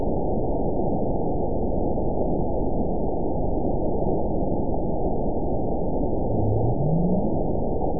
event 912179 date 03/20/22 time 02:39:52 GMT (3 years, 2 months ago) score 9.71 location TSS-AB04 detected by nrw target species NRW annotations +NRW Spectrogram: Frequency (kHz) vs. Time (s) audio not available .wav